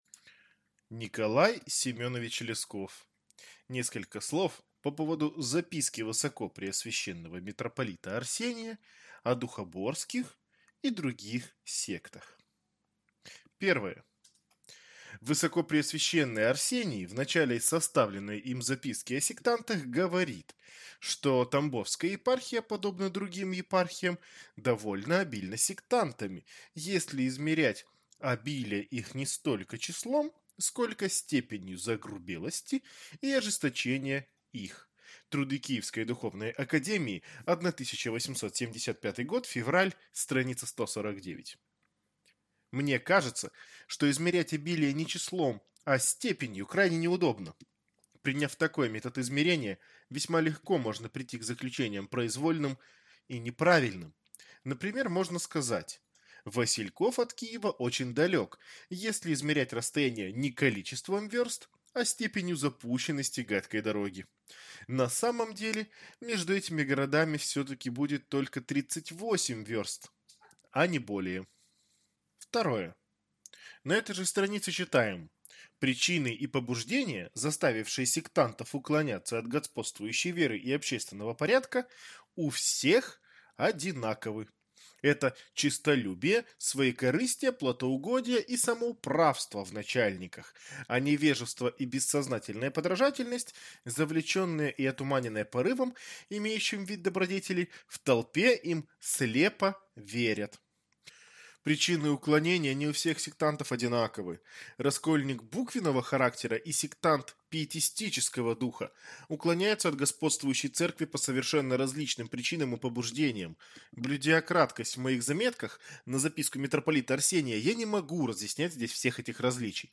Аудиокнига Несколько слов о духоборских и других сектах | Библиотека аудиокниг
Прослушать и бесплатно скачать фрагмент аудиокниги